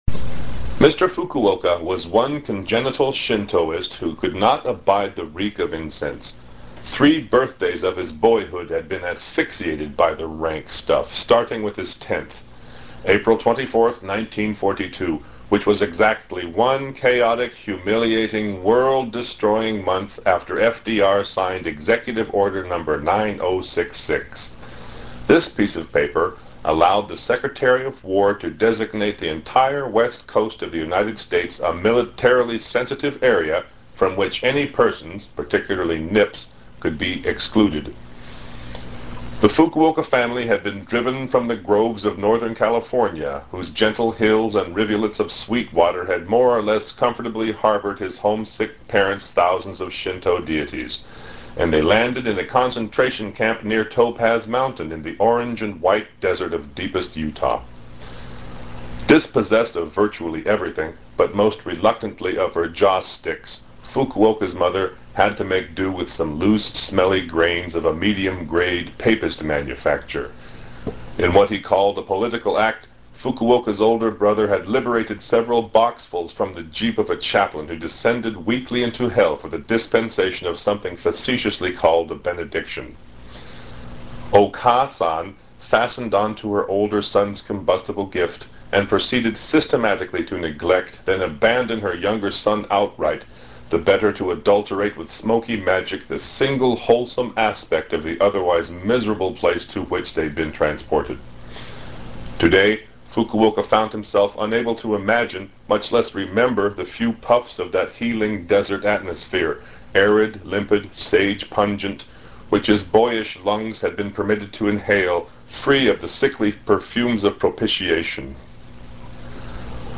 videos and recorded readings.